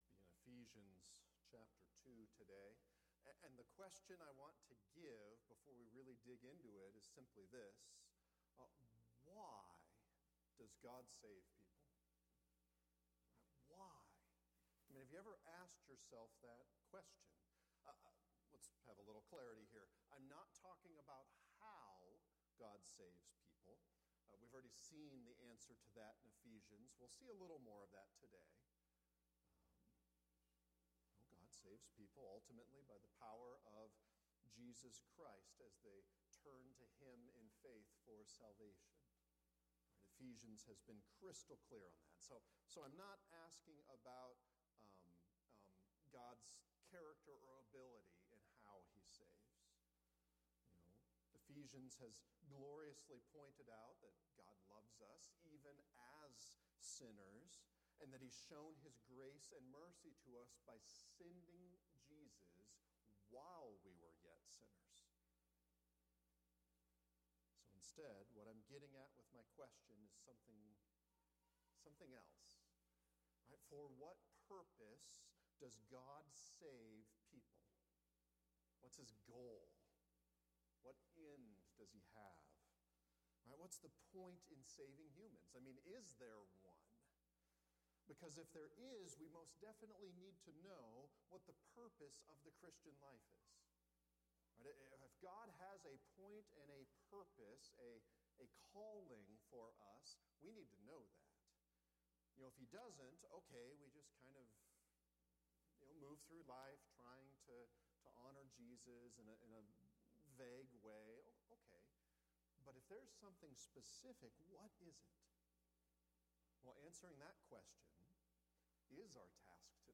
Ephesians 2:8-10 Saved for a Purpose – Sermons